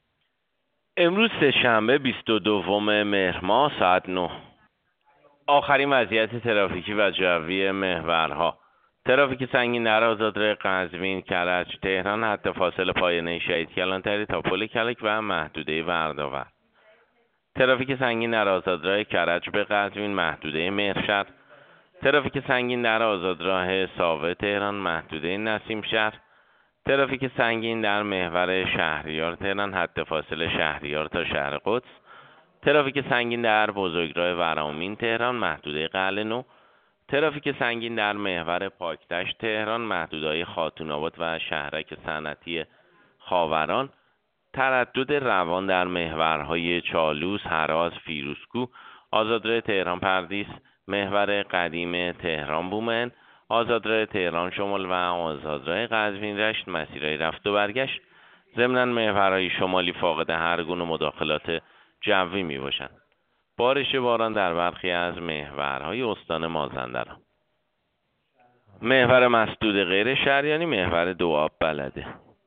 گزارش رادیو اینترنتی از آخرین وضعیت ترافیکی جاده‌ها ساعت ۹ بیست‌ودوم مهر؛